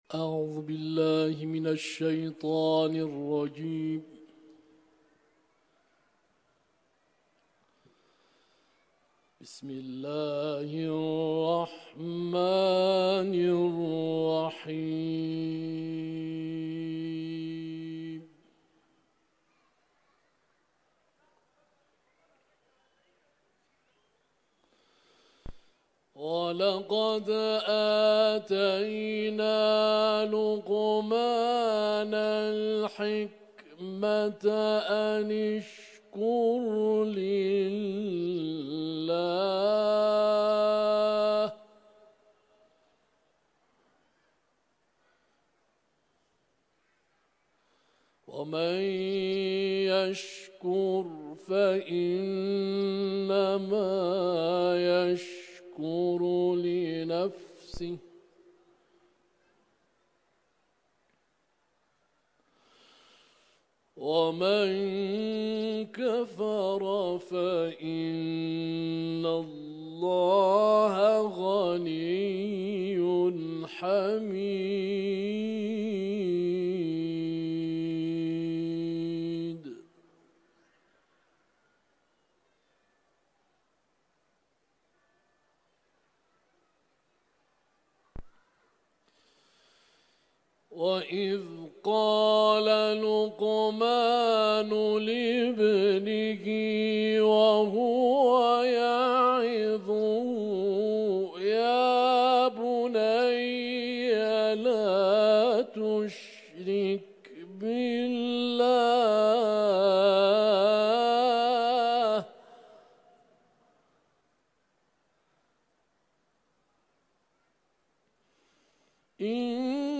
تلاوت قرآن
سوره لقمان ، حرم مطهر رضوی